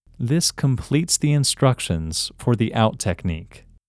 OUT – English Male 34